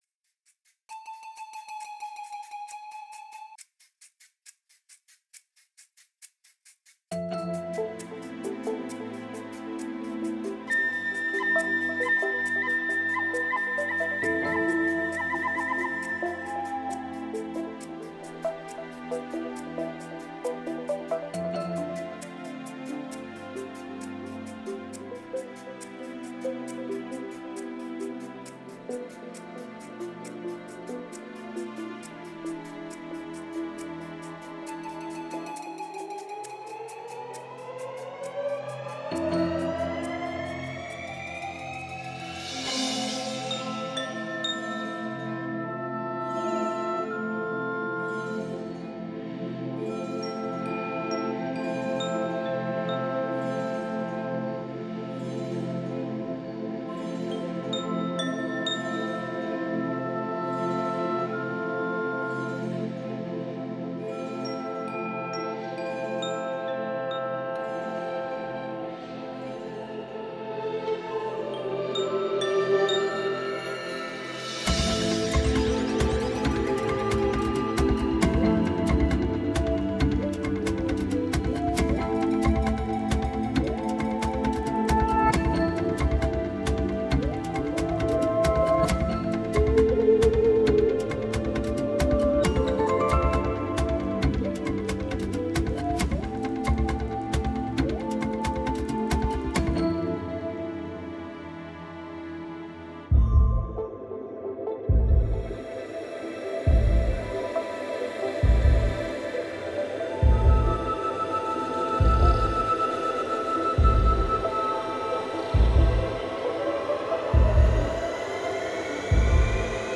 Versione strumentali (no voce)